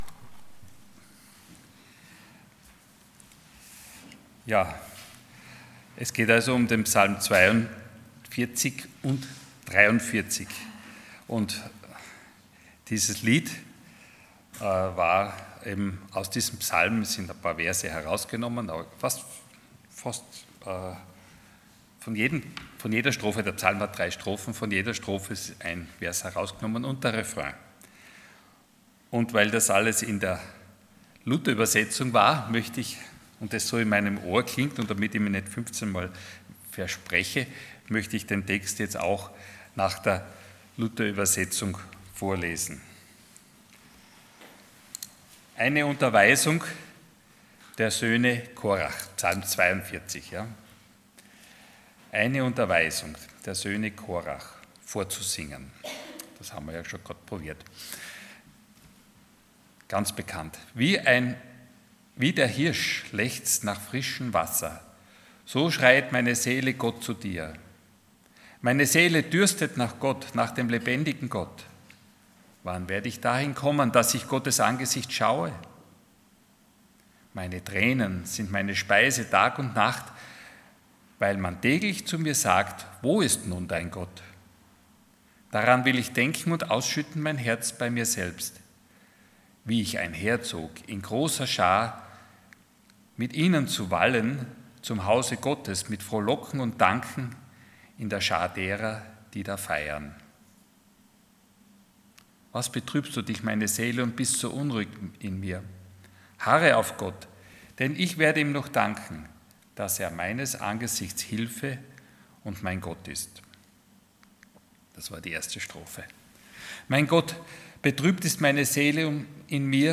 Passage: Psalm 42:1-43:5 Dienstart: Sonntag Morgen